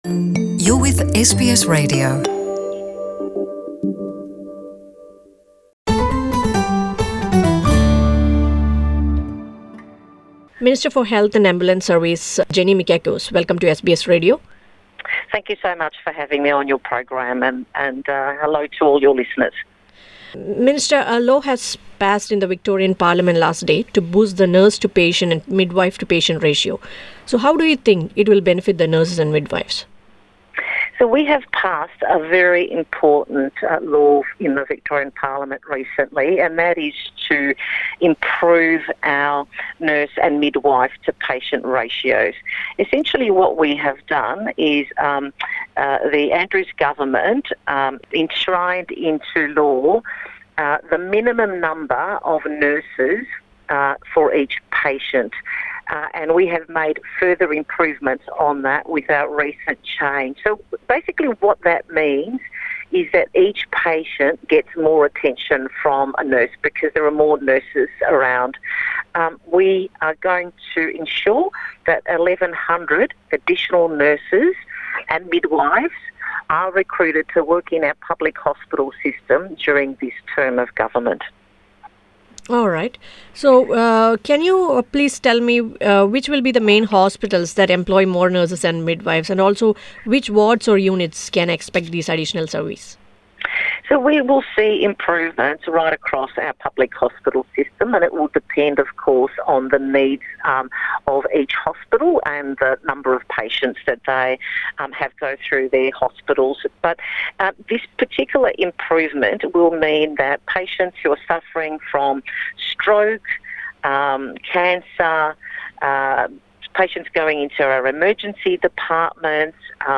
Victorian Minister for Health and Ambulance Services Jenny Mikakos speaks to SBS Malayalam about the new plan to give more jobs to the nurses and midwives with the aim of boosting the nurse to patient ratio.